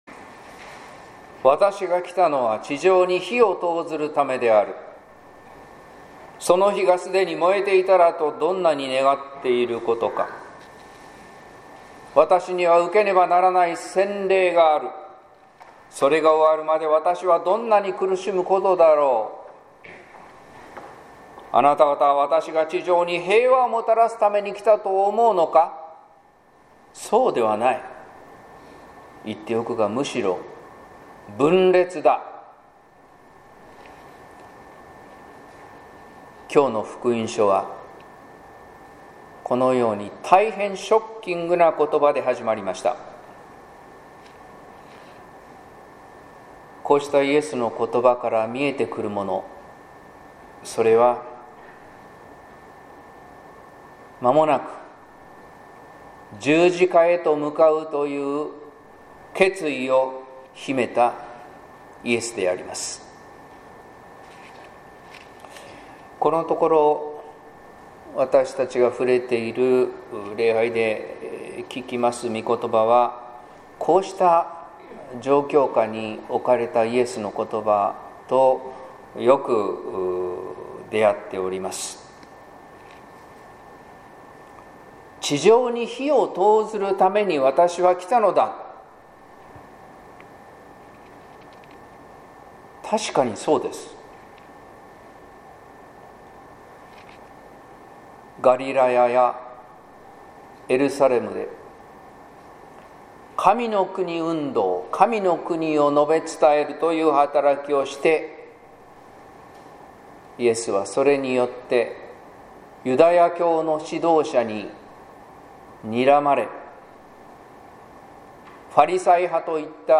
説教「イエスの今の時」（音声版）